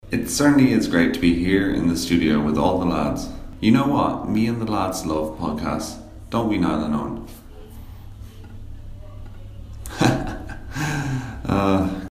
Tags: interview